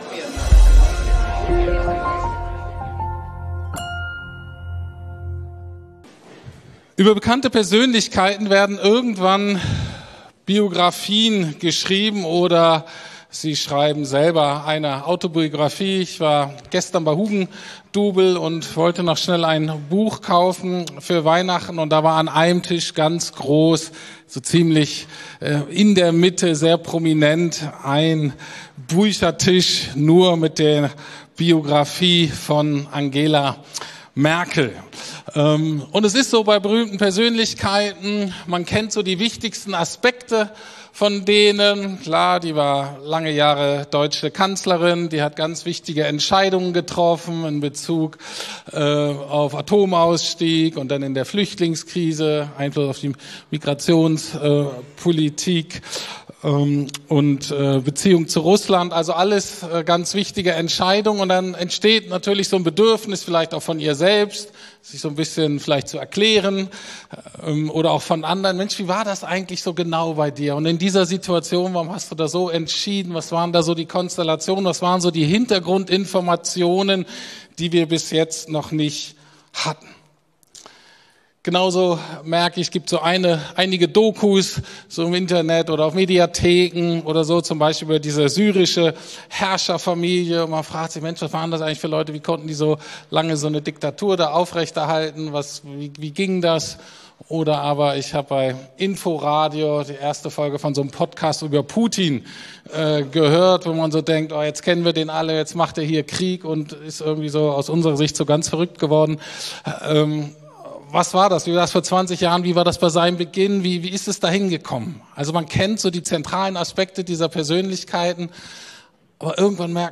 Der Anfang nach Lukas ~ Predigten der LUKAS GEMEINDE Podcast